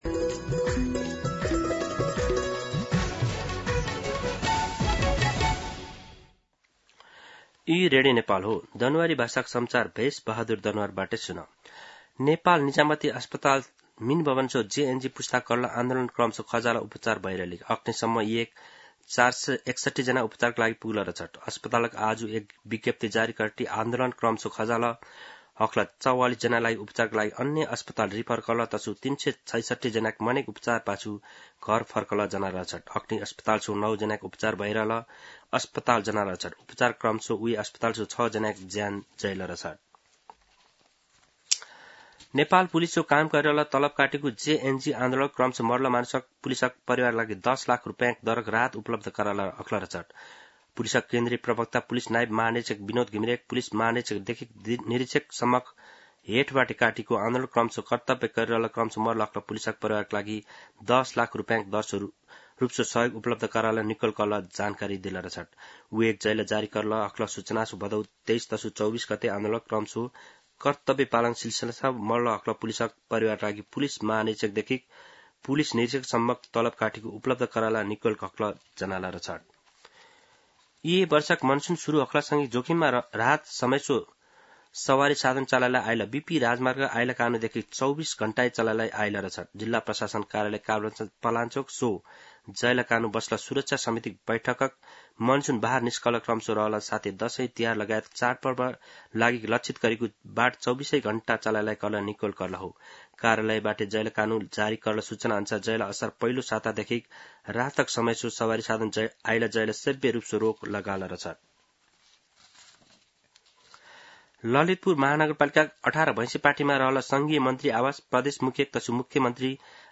दनुवार भाषामा समाचार : ३१ भदौ , २०८२
Danuwar-News-3.mp3